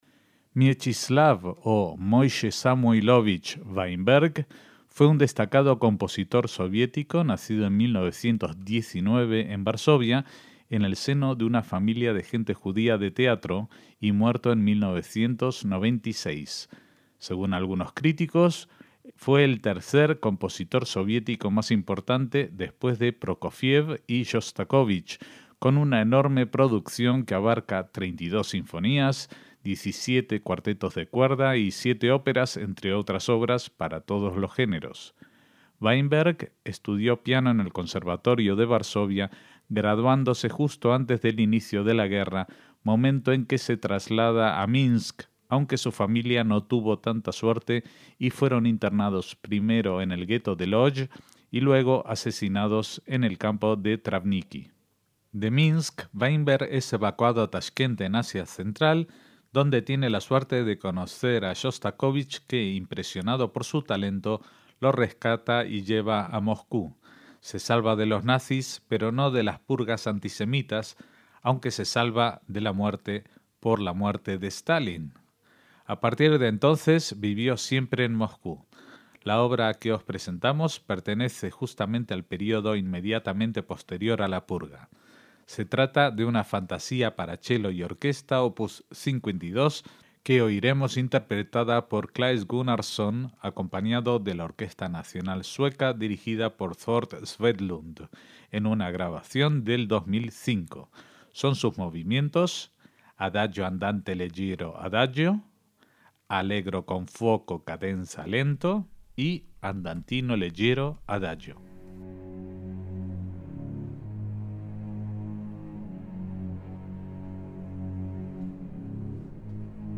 La Fantasía para chelo y orquesta, Op. 52, de Mieczyslaw Weinberg